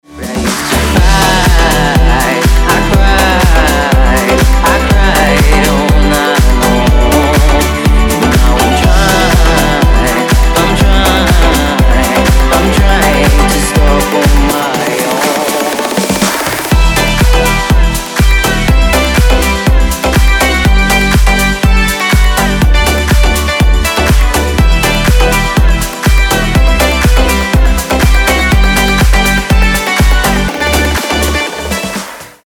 • Качество: 320, Stereo
dance
красивый женский голос
house